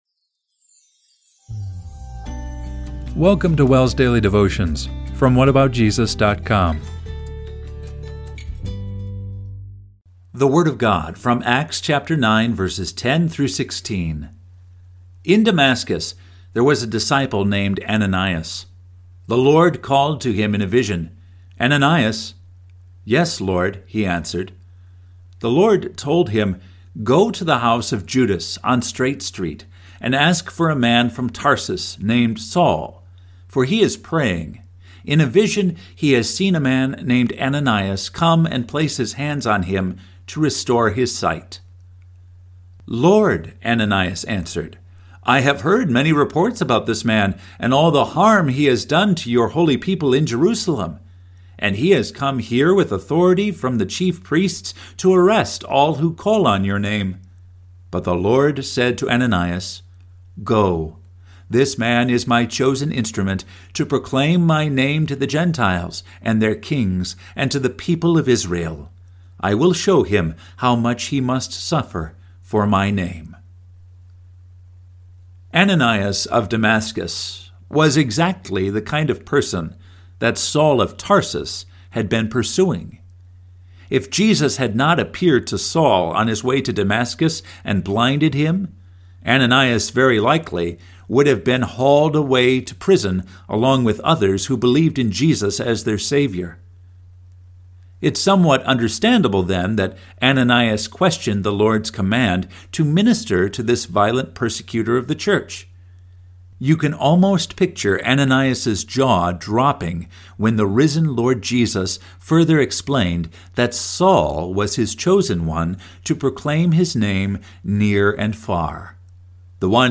Daily Devotion – May 7, 2025